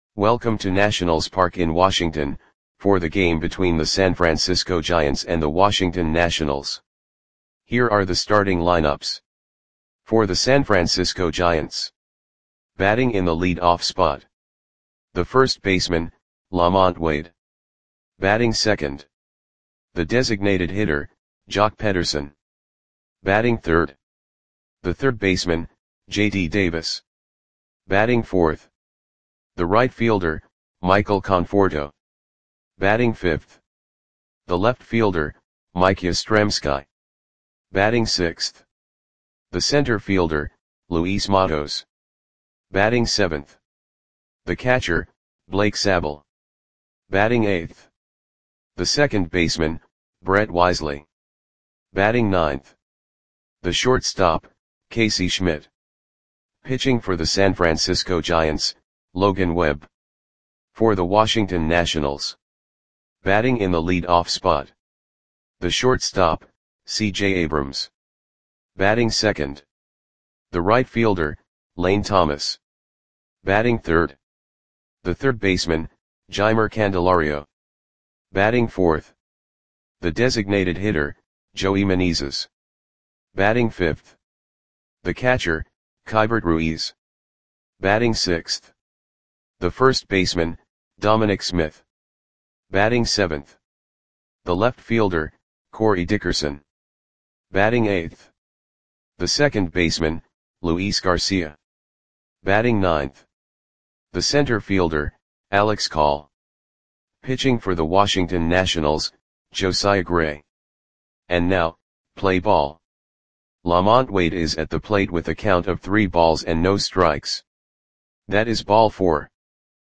Audio Play-by-Play for Washington Nationals on July 22, 2023
Click the button below to listen to the audio play-by-play.